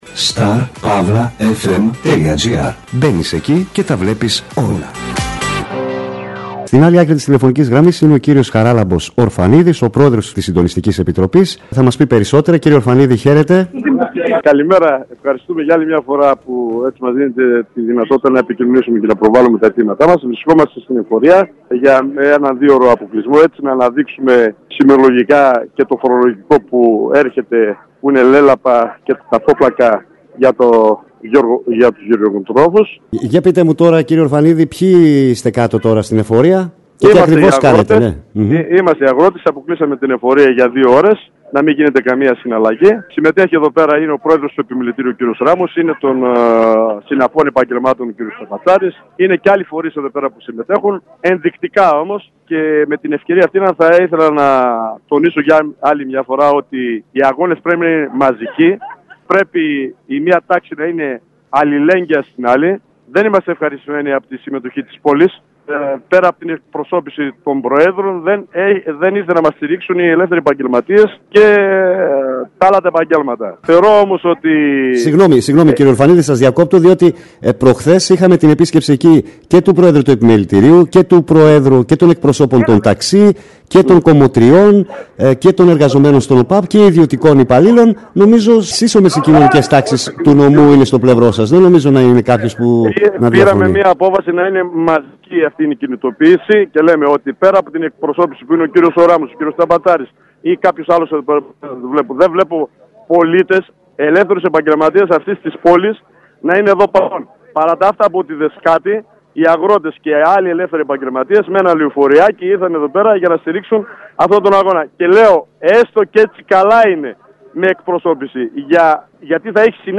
Aκούστε τι δήλωσαν νωρίτερα στον Starfm 933 οι εκπρόσωποι των φορέων